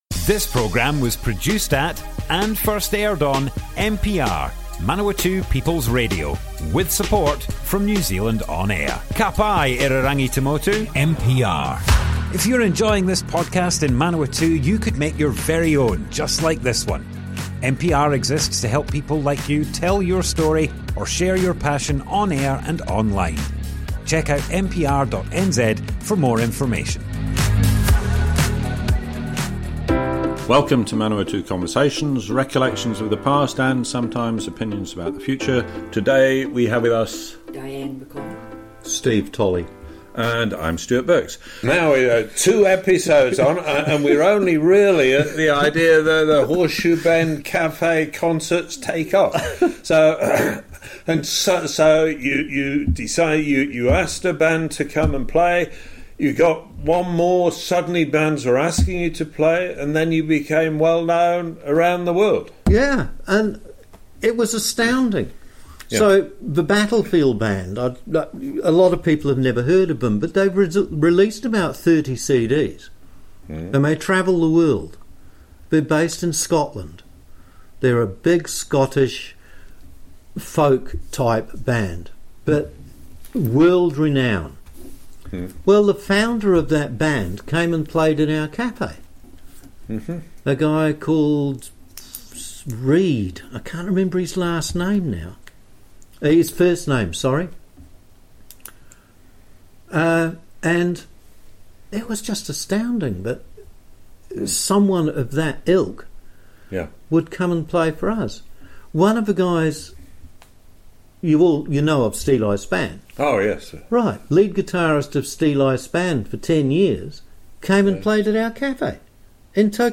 Manawatu Conversations More Info → Description Broadcast on Manawatu People's Radio, 9th September 2025.
oral history